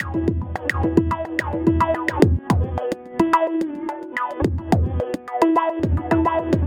IND. SITAR-R.wav